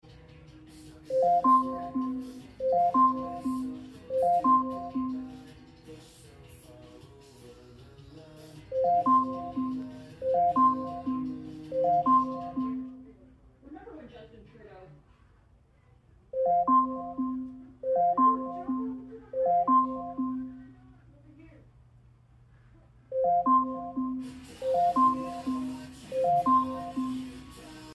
Alexa Alarm Sound Sound Effects Free Download